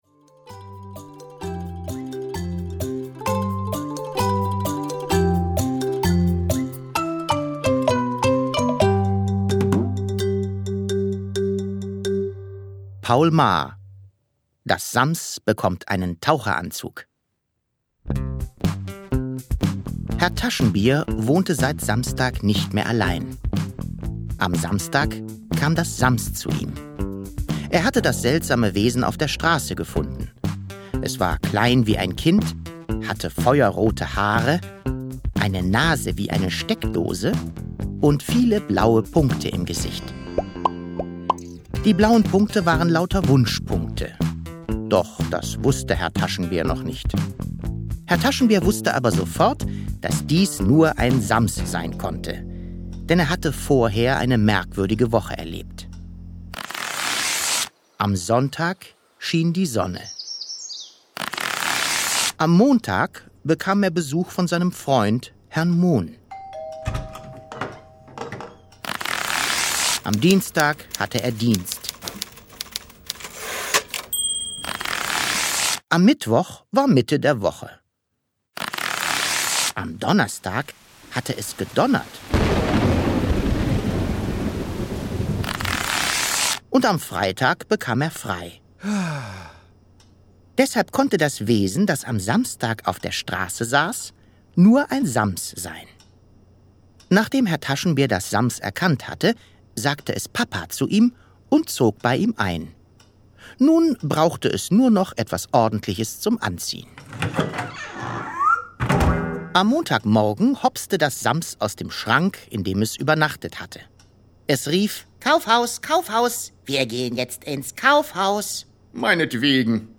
Schlagworte Abenteuer • Freundschaft • Herr Taschenbier • Hörbuch; Lesung für Kinder/Jugendliche • Humor • Idee • Junge • Kinderbuch • Kleidung • Pech • Phantastisches • Sammelband • SAMs • schlechte Laune • Tauchanzug • Wünsche • Wunschpunkte • Wut